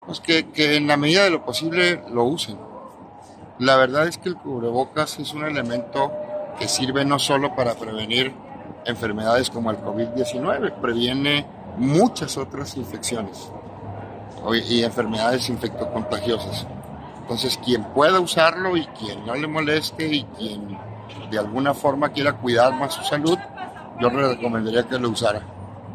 CHIHUAHUA.- Luego de que hoy el uso de cubrebocas en espacios abiertos y cerrados perdiera su obligatoriedad, el secretario General de Gobierno, César Jáuregui Moreno, se dirigió a la ciudadanía para recomendarle seguir con esta medida preventiva.